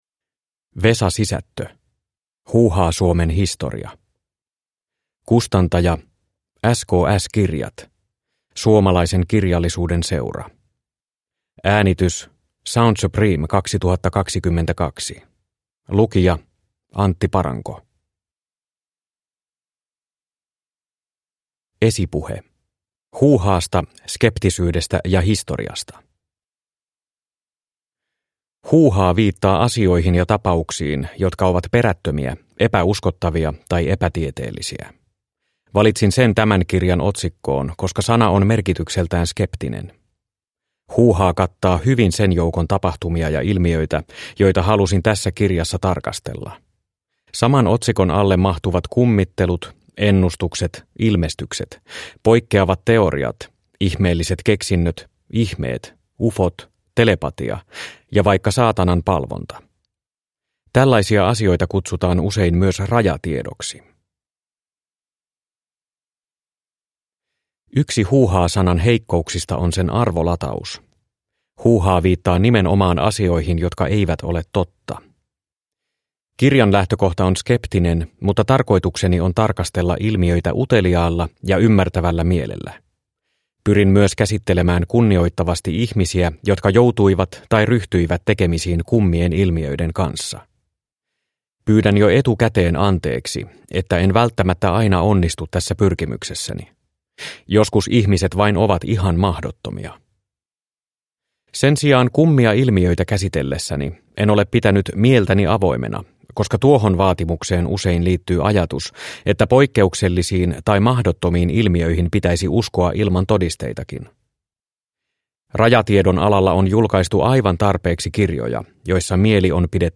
Huuhaa-Suomen historia – Ljudbok – Laddas ner